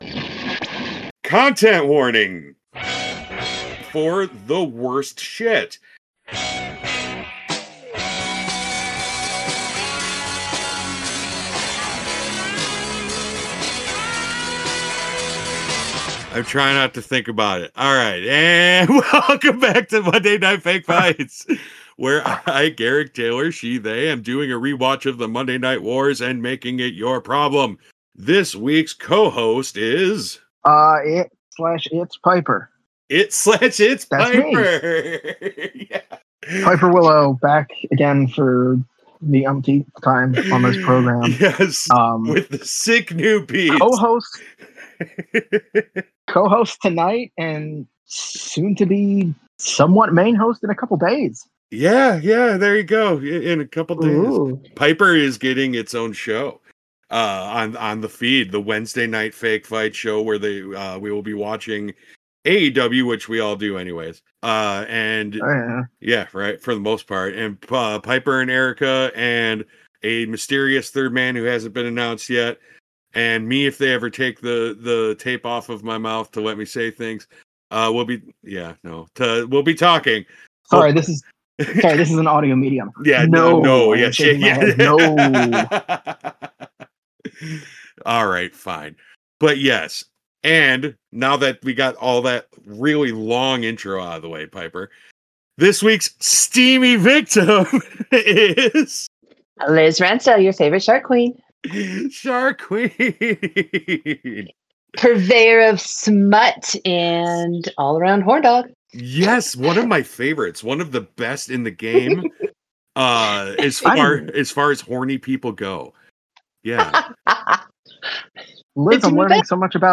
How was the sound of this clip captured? Broadcast on Ghost Coast Radio